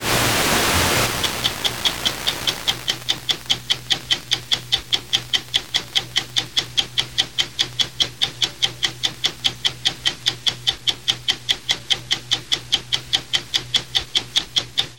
This aircheck was recorded from my home, which is about 19 miles from the transmitter site, using a 10 element log-Yagi antenna. Reception is extremely good, considering the low power of this station.
mono recording of the tick-tock sound effect heard at the beginning of the broadcast. Note how much less background noise there is when the station is received in mono.
KISN-LP_ticktock_mono.mp3